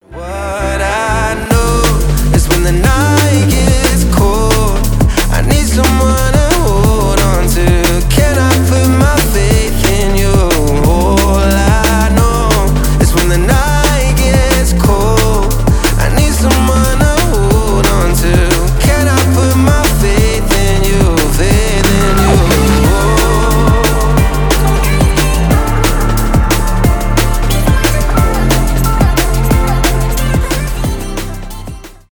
драм энд бейс
красивый мужской голос , танцевальные